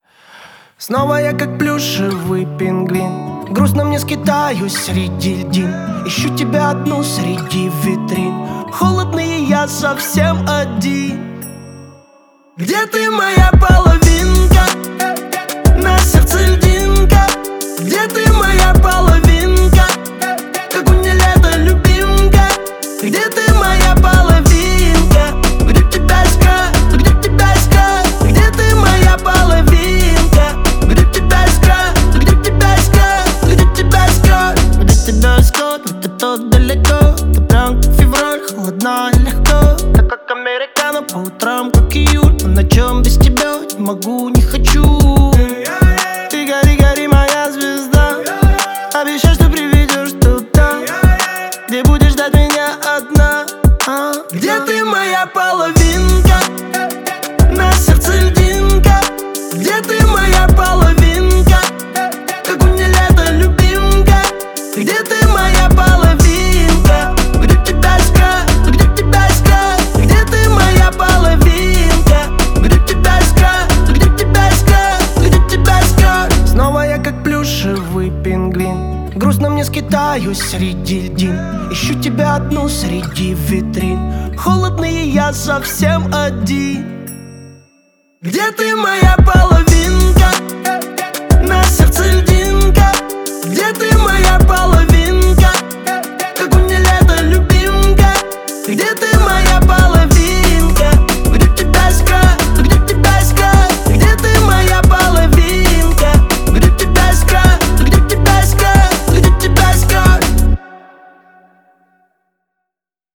это яркая и запоминающаяся песня в жанре поп